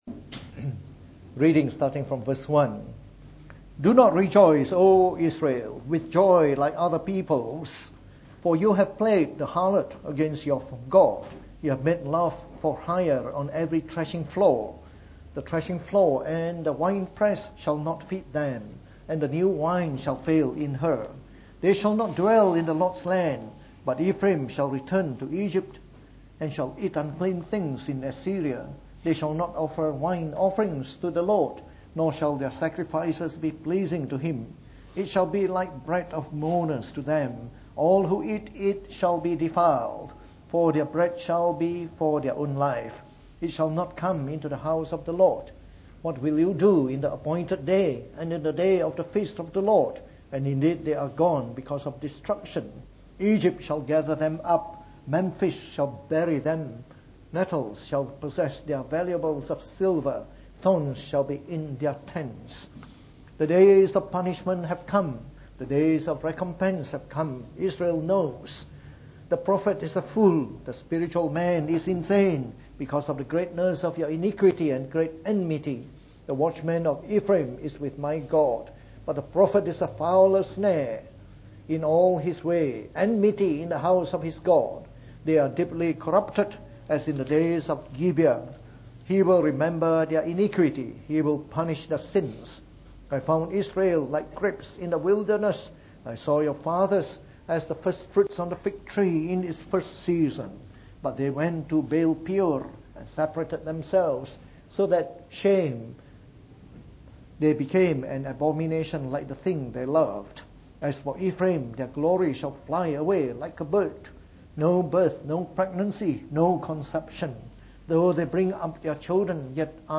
From our series on the Book of Hosea delivered in the Morning Service.